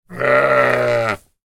Sheep-baa-sound-effect.mp3